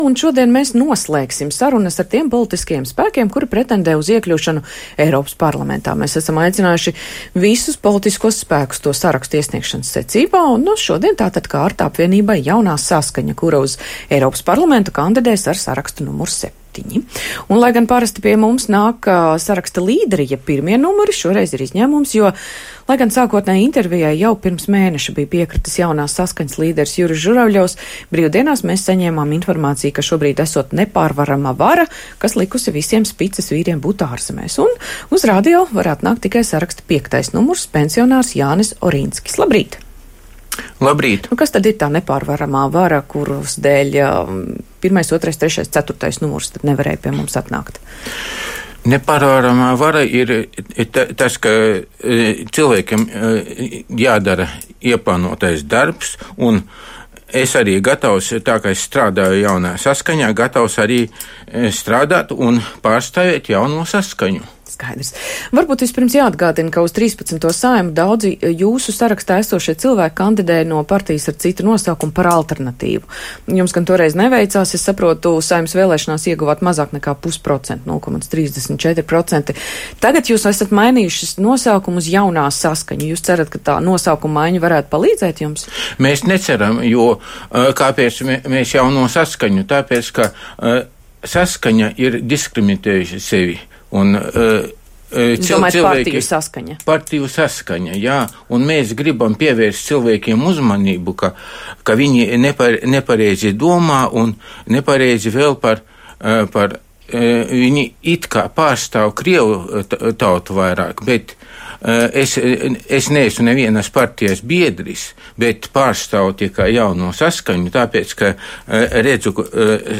intervijā Latvijas Radio